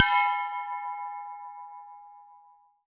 bell_med_ringing_02.wav